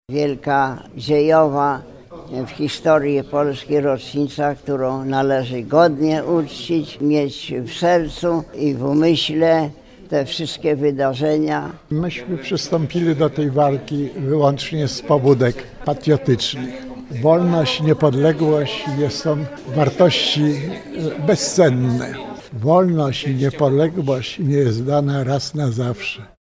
Powstańcy warszawscy apelują o godne uczczenie dzisiejszej rocznicy: